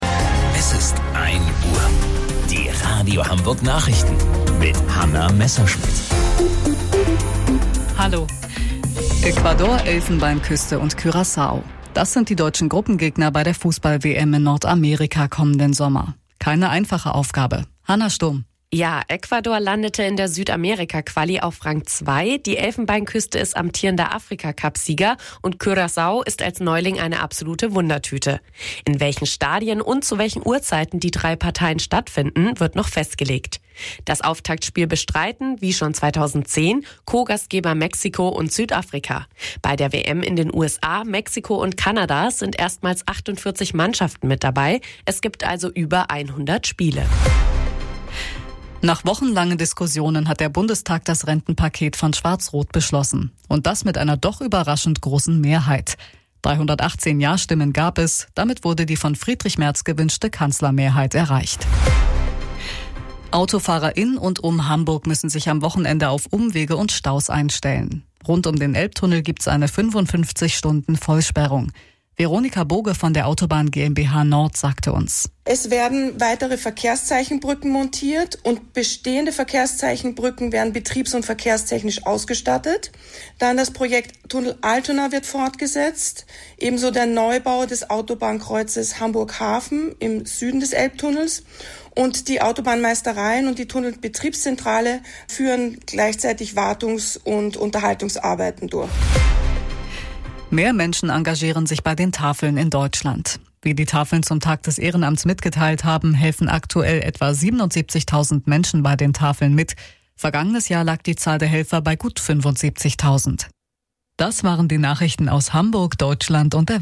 Radio Hamburg Nachrichten vom 06.12.2025 um 01 Uhr